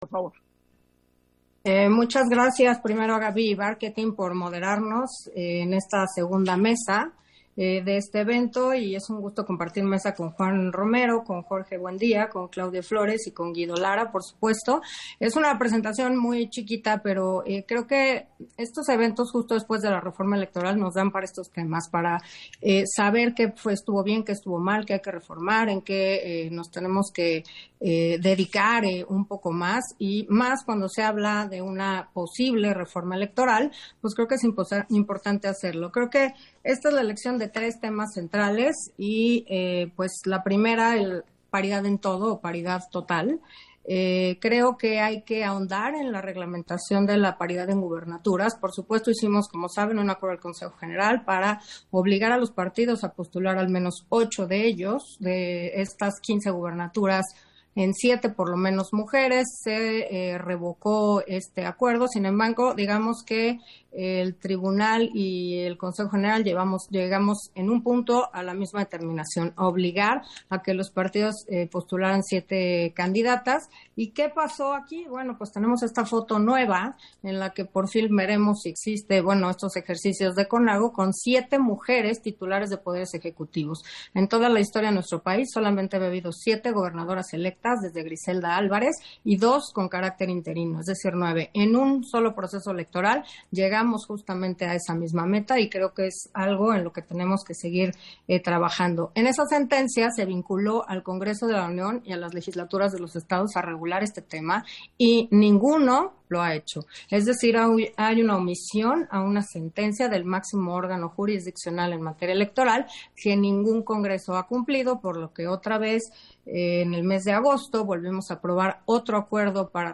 Intervención de Carla Humphrey en la mesa, Reforma Electoral y prospectiva, en el marco del Foro Encuestas y Elecciones 2021